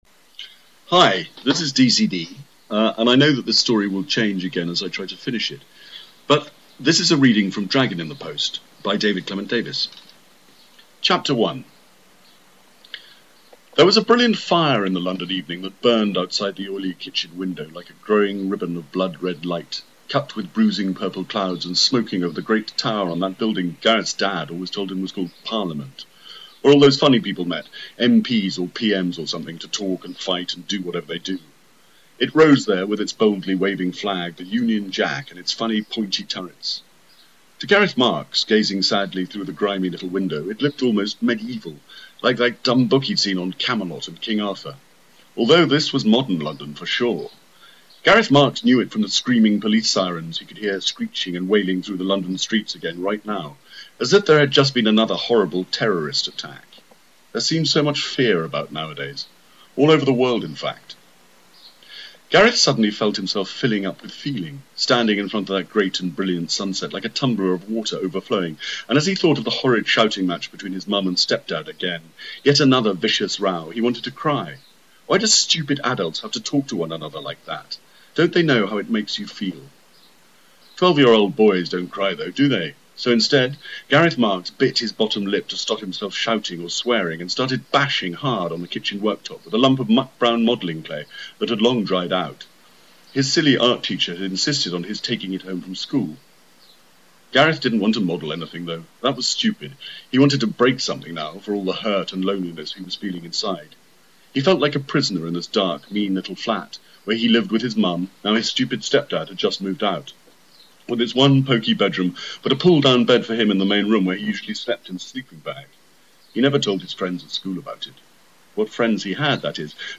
A PRICE DROP, THE FULL DRAGON READING, YOUR HELP, JUST 2 WEEKS LEFT AND THE IDEA OF BOOKS BY SUBSCRIPTION
dragonreading11.mp3